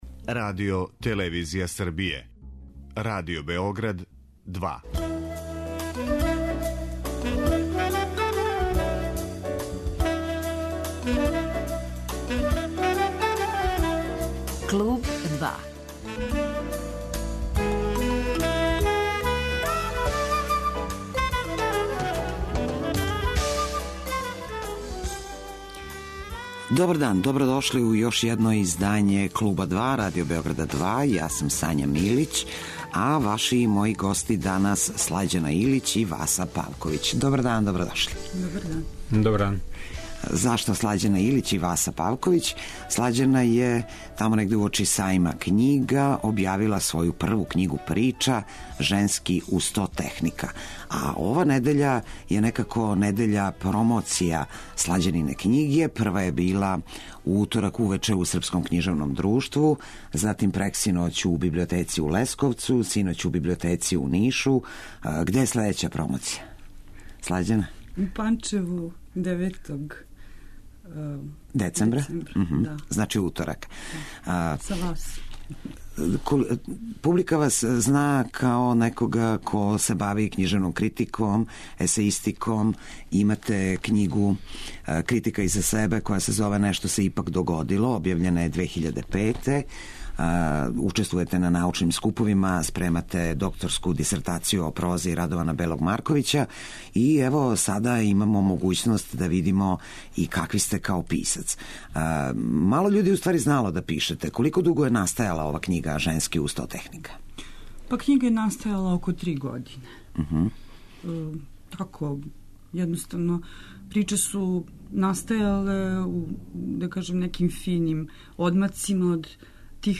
Гости емисије биће књижевница